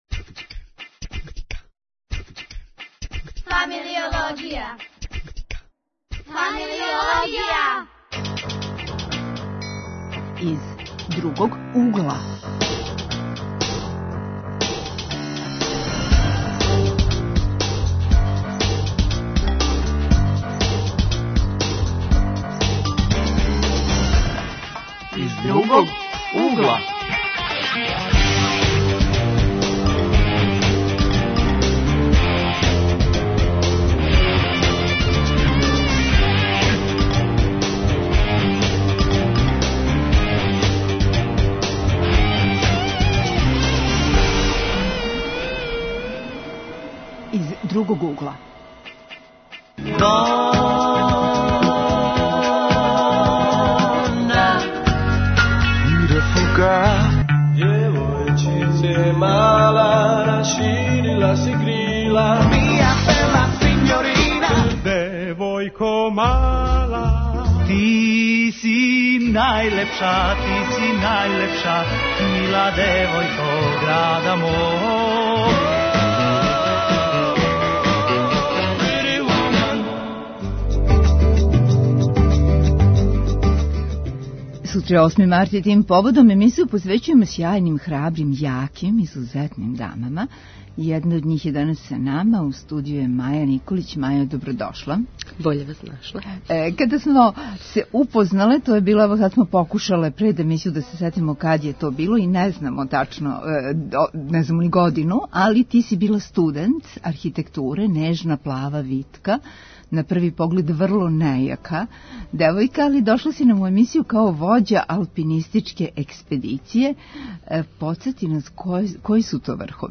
Гости у студију су студенти